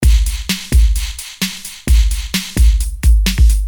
Free MP3 electro drumloops soundbank 2
Electro rythm - 130bpm 23